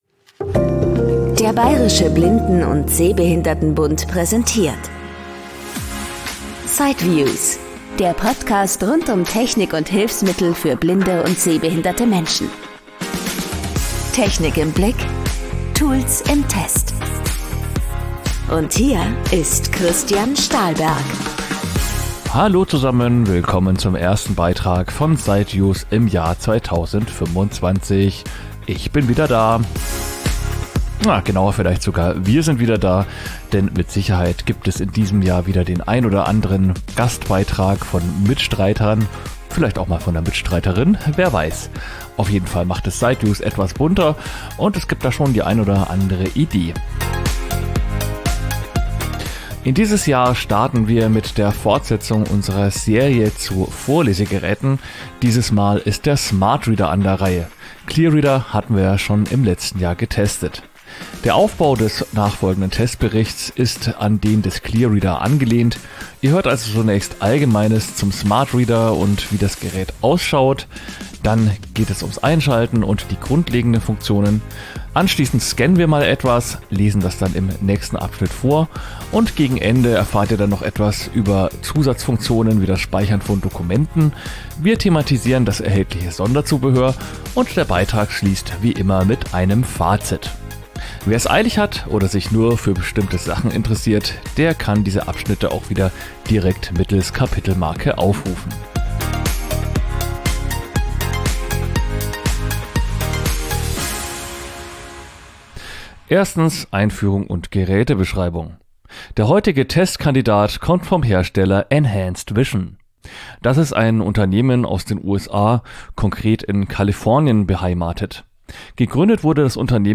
Sightviews ist ein firmenunabhängiger Podcast rund um Technik und Hilfsmittel für blinde und sehbehinderte Menschen. Kern des Podcasts sind Berichte und Interviews von der Sightcity in Frankfurt, der weltweit größten Messe zu diesem Themenfeld.